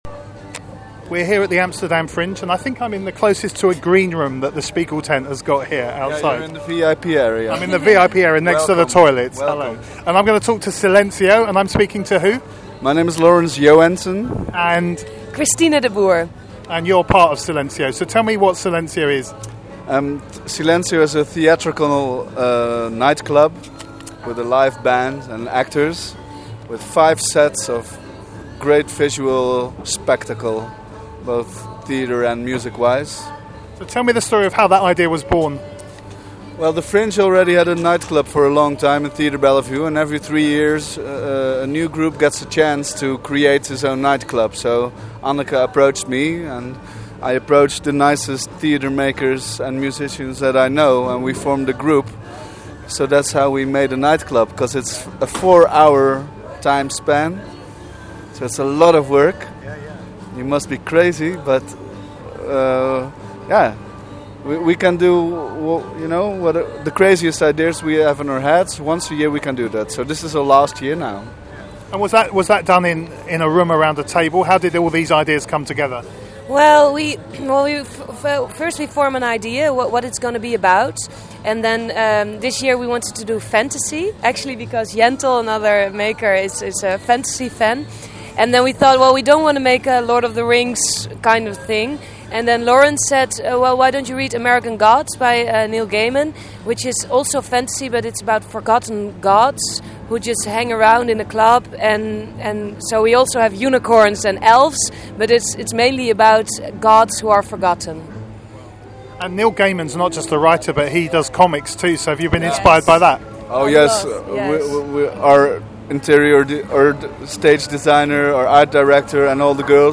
Amsterdam Fringe 2014, Audio Interviews